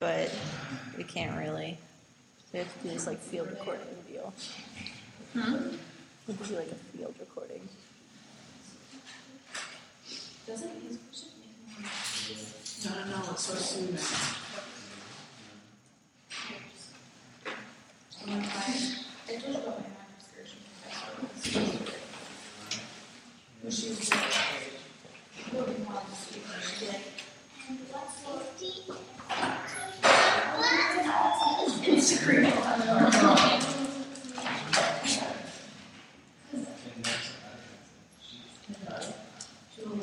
Field Recording Number 10
Location: Gallon Wing, Hofstra University
Sounds heard: people talking, keys jingling, door opening and closing, laughter.